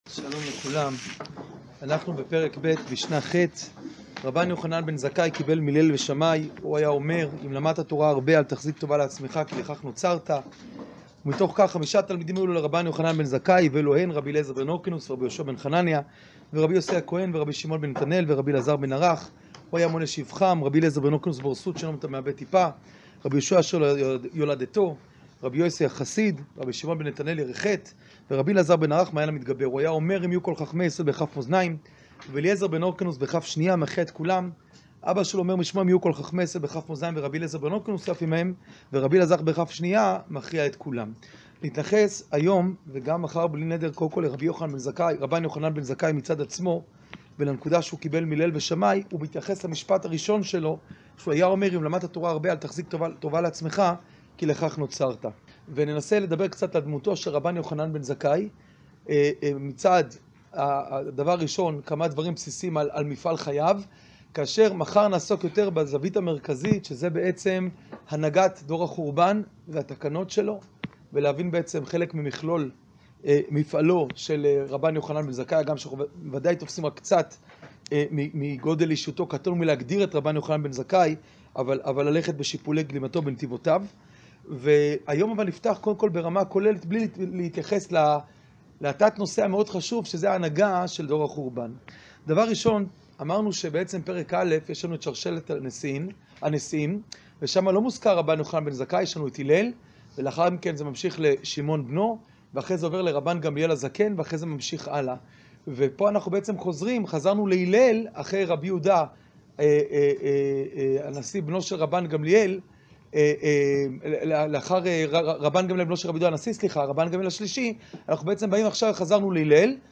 שיעור פרק ב משנה ח